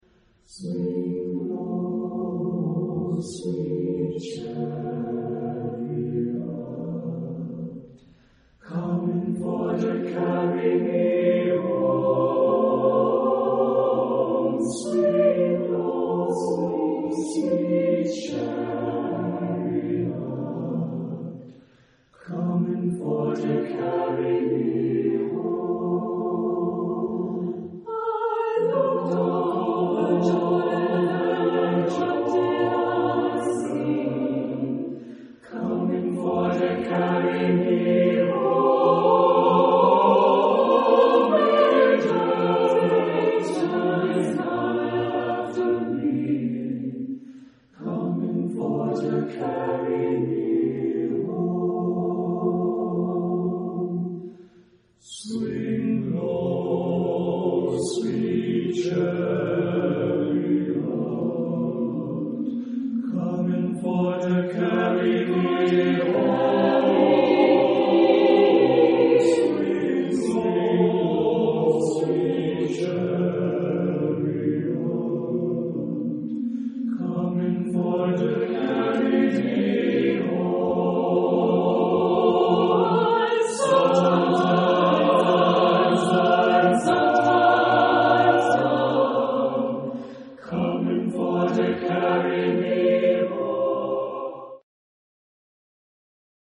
SATB (4 voices mixed).
Spiritual.
sung by Susquehanna Chorale (USA)
Consultable under : Spirituals Gospels Acappella Location of the CD: CD-0072 Consultable under : JS-Spirituals